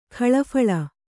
♪ khaḷa phaḷa